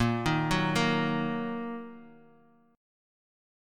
A#sus4#5 chord